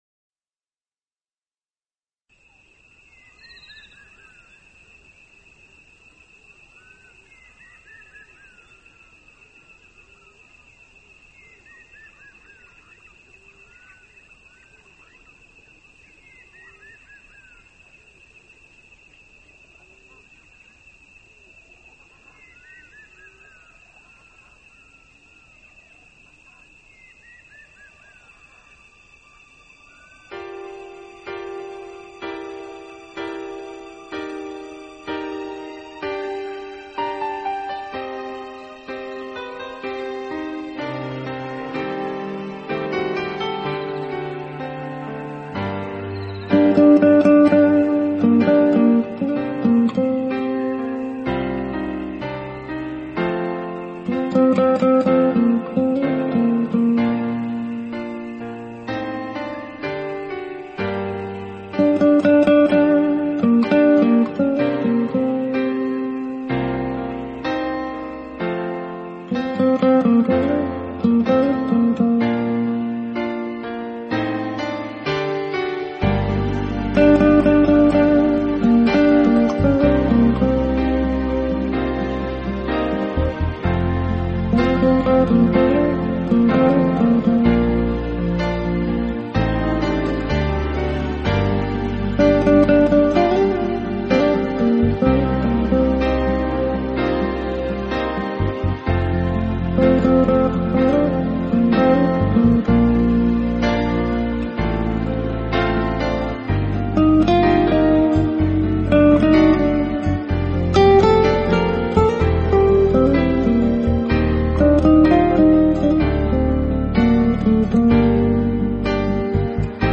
纯音乐
简单而清新的吉它声，慵懒节奏舒解内心的疲惫，撩动各种怀旧声响制造的甜蜜音